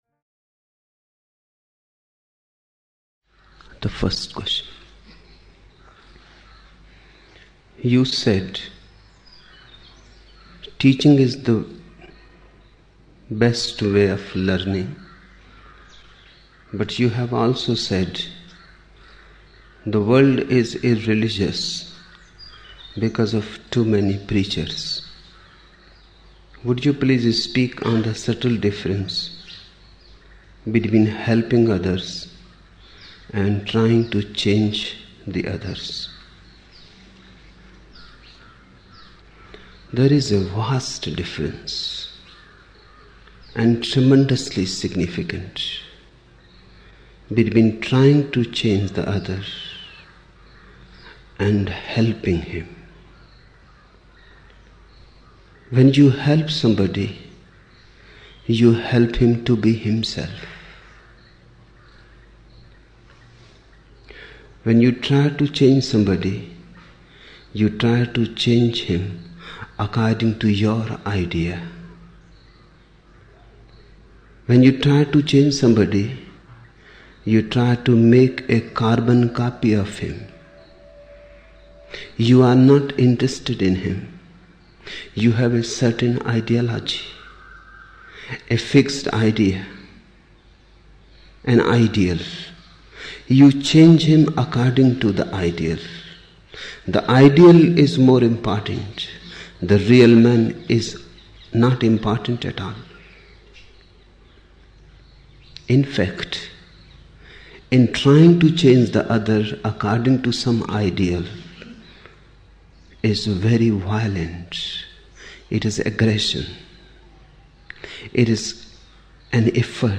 5 November 1975 morning in Buddha Hall, Poona, India